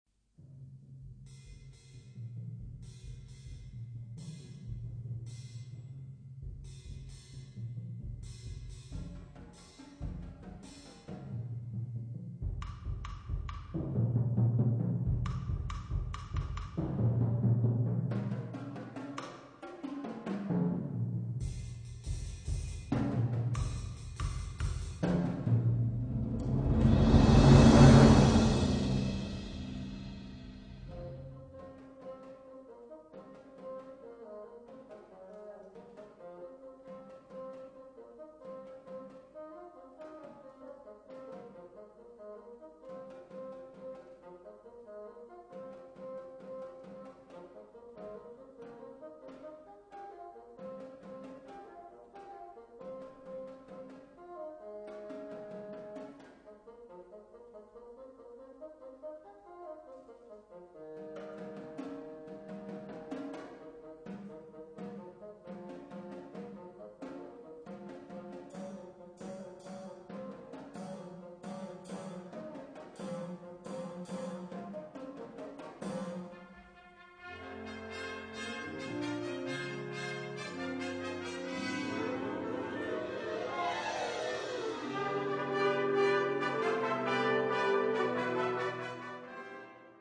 Categoria Concert/wind/brass band